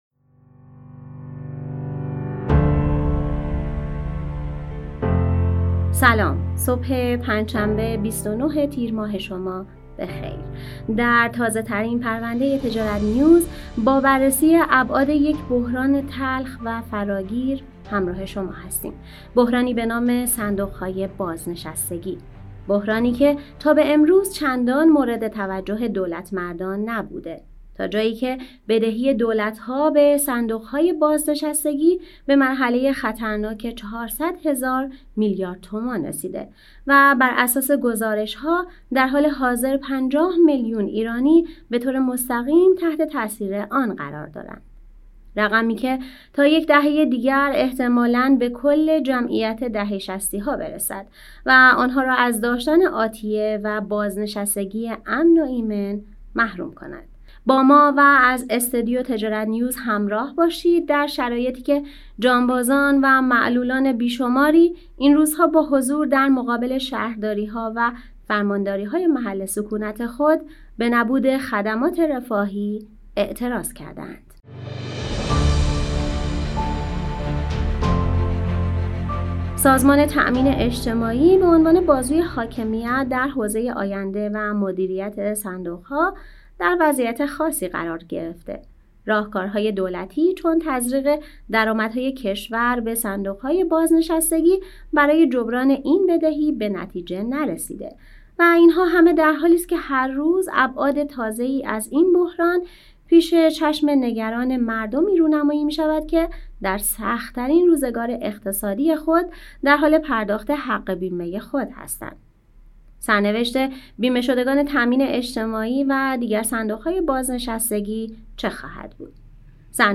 با ما و از استودیو تجارت‌نیوز همراه باشید در شرایطی که جانبازان و معلولان بی‌شماری این روزها با حضور در مقابل